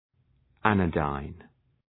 Προφορά
{‘ænə,daın}